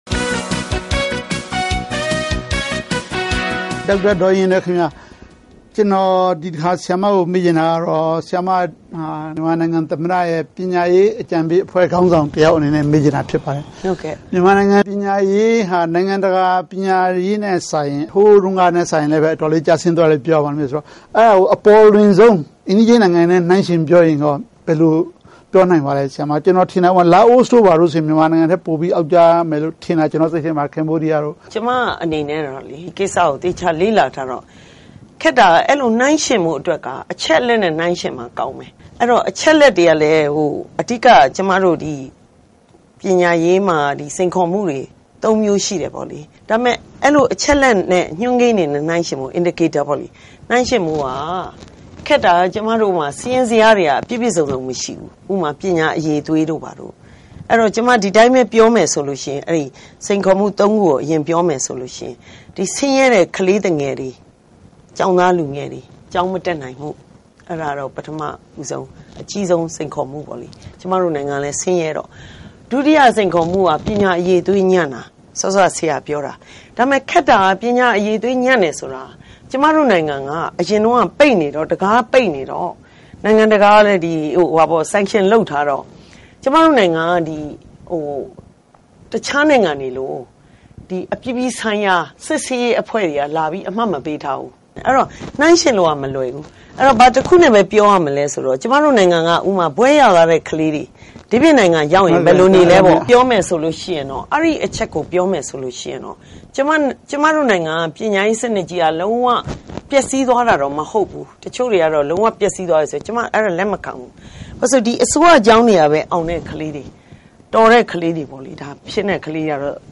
တွေ့ဆုံမေးမြန်းခန်း